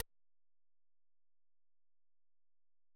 UI Click 1.wav